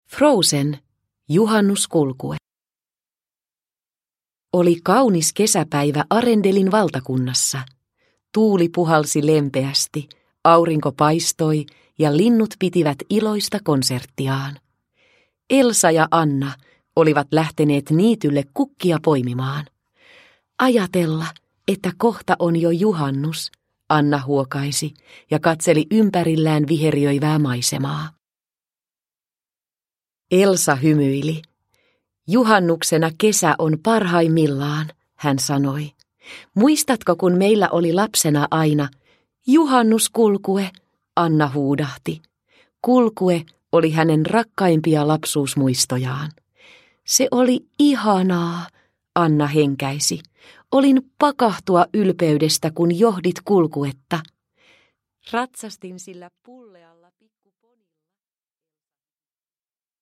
Frozen. Juhannuskulkue – Ljudbok – Laddas ner